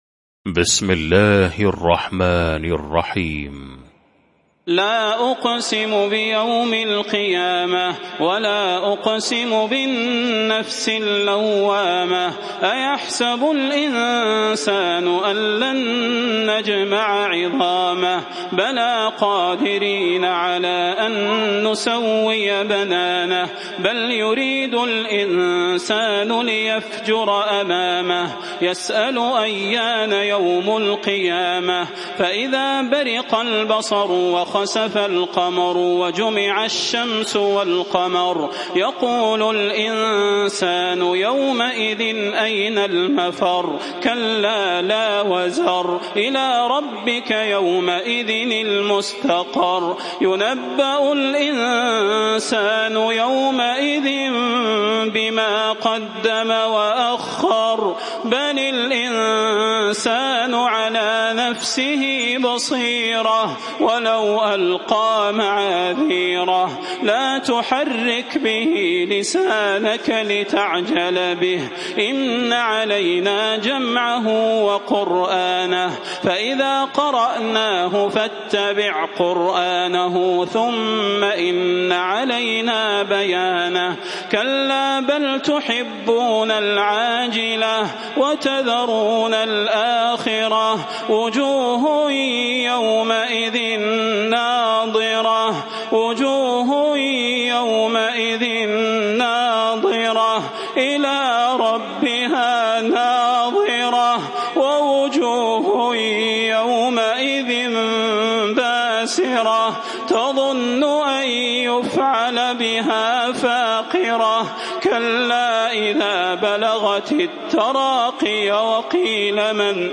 المكان: المسجد النبوي الشيخ: فضيلة الشيخ د. صلاح بن محمد البدير فضيلة الشيخ د. صلاح بن محمد البدير القيامة